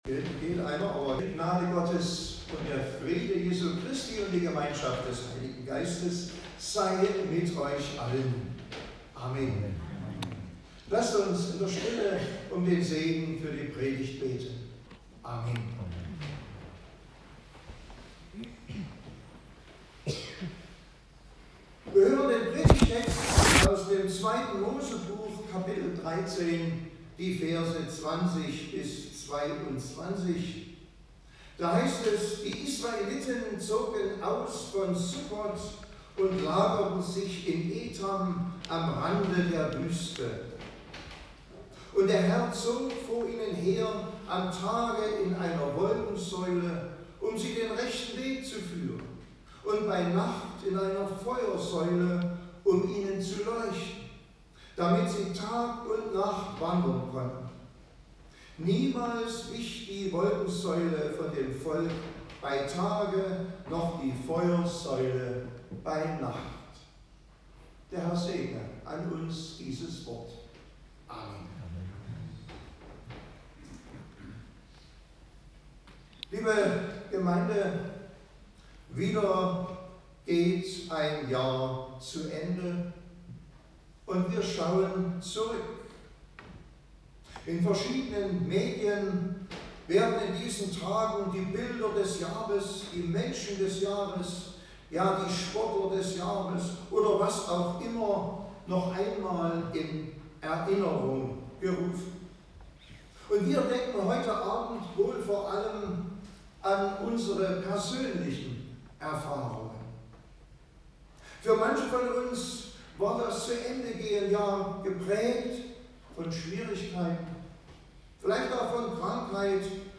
Passage: 2. Mose 13; 20-22 Gottesdienstart: Predigtgottesdienst Wildenau « Weihnachten: das Fest des kleinen Anfangs, das Fest des guten Hirtens, das Fest des tiefen Friedens Christus spricht: Ich habe für dich gebeten, dass dein Glaube nicht aufhöre.